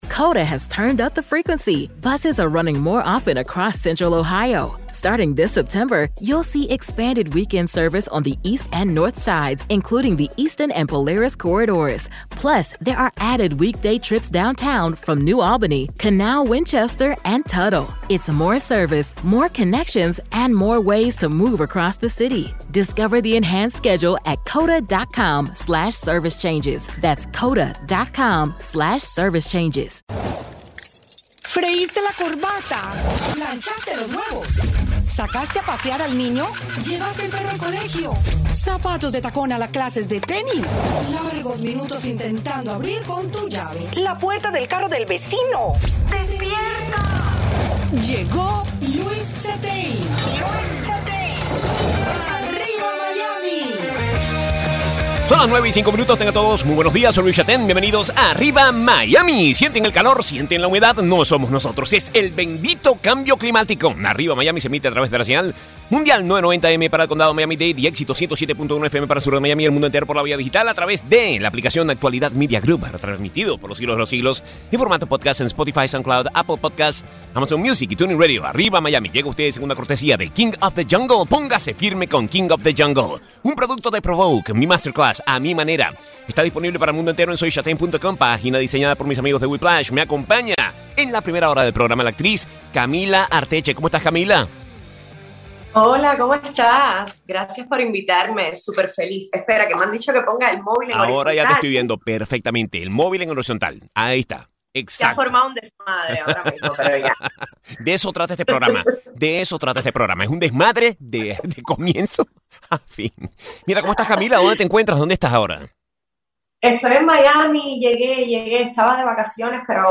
Conversa con la cantante y compositora Lena Burke.